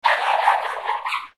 AI Stanley Woofs.mp3